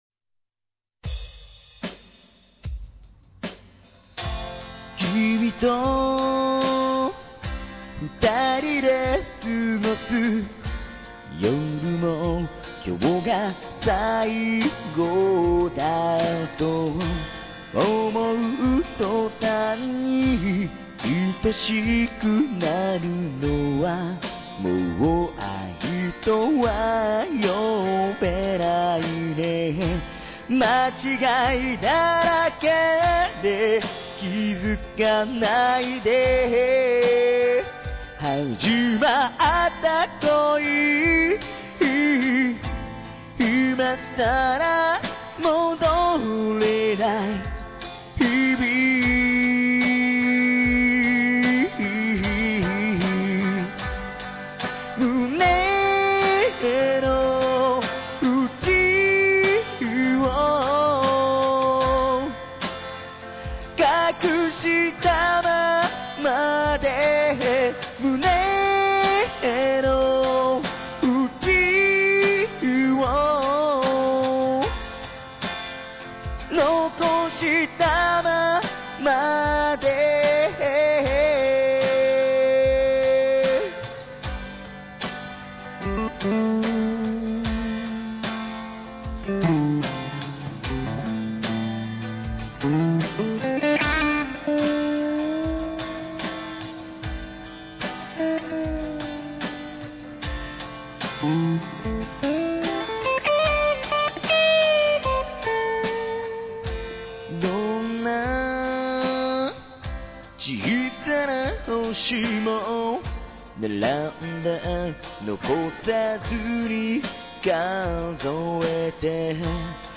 Guiter
珍しい失恋バラードです。
しっとりと。